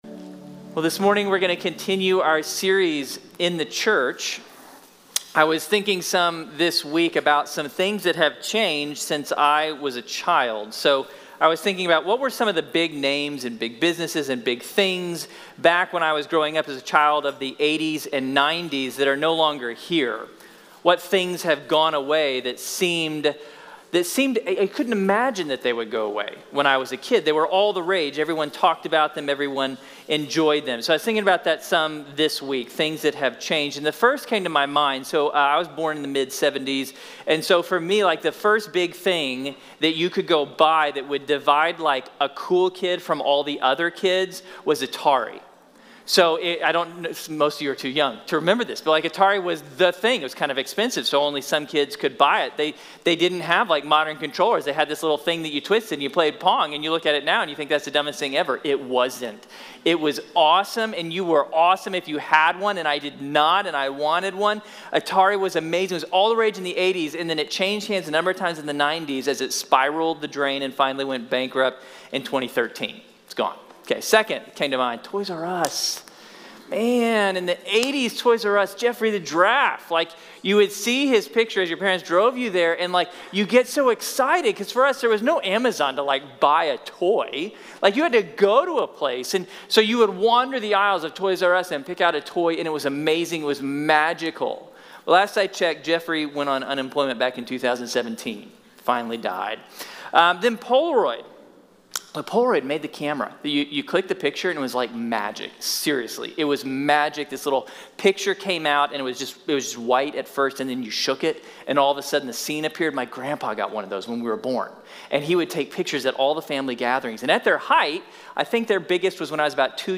| Sermon | Grace Bible Church